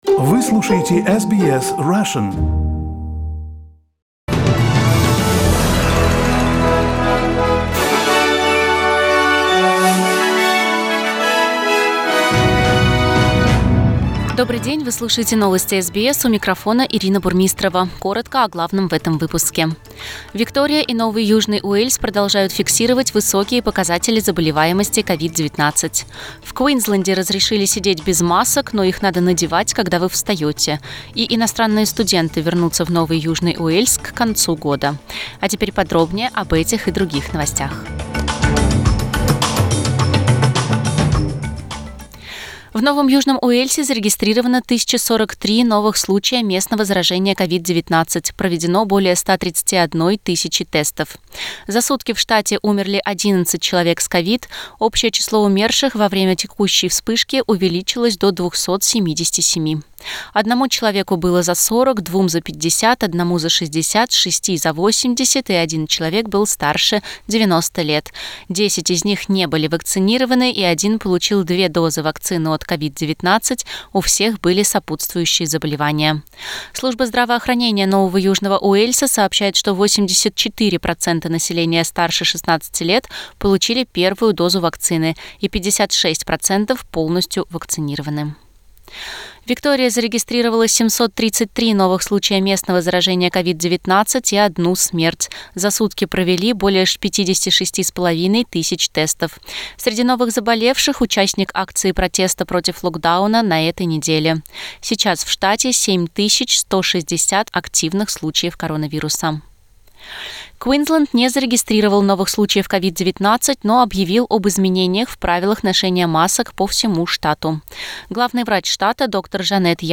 SBS news in Russian - 24.09